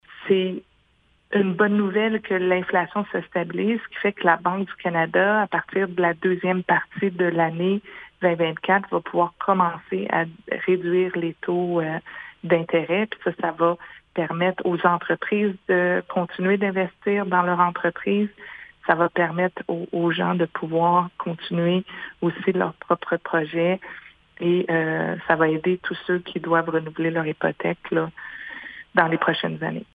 Ces deux annonces de la Banque du Canada pourraient être le début d’une sortie de crise pour les citoyens qui paient déjà plus cher leurs hypothèques ou leur panier d’épicerie, a expliqué la députée :